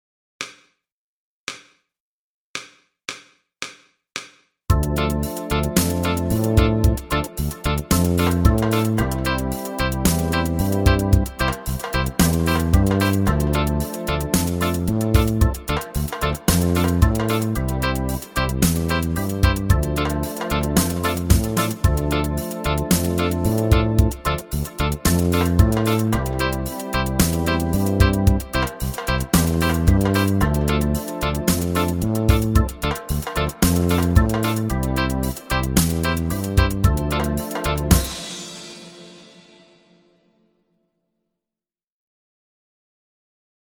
1 Gitarre
• Thema: Gitarre Schule